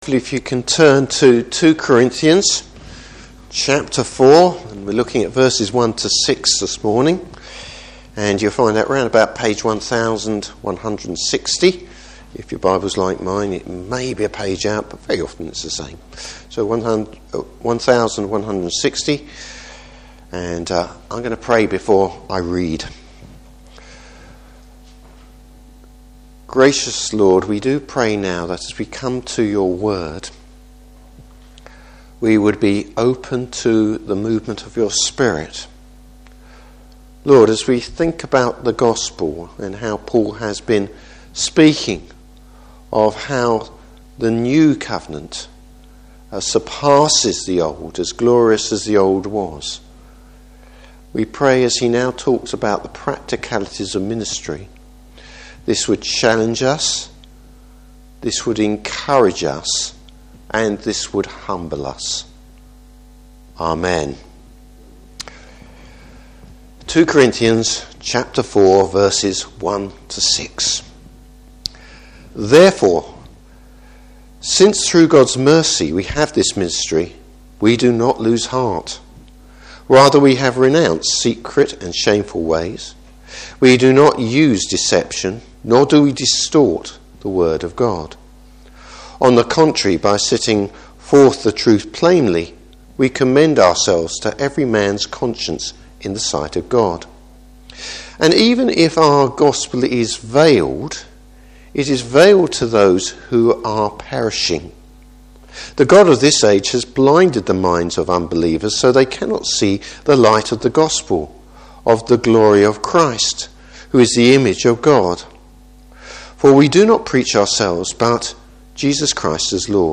Service Type: Morning Service The nature of the ministry of God’s Word.